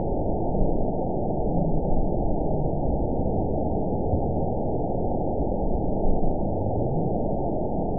event 912271 date 03/23/22 time 11:35:39 GMT (3 years, 2 months ago) score 9.54 location TSS-AB02 detected by nrw target species NRW annotations +NRW Spectrogram: Frequency (kHz) vs. Time (s) audio not available .wav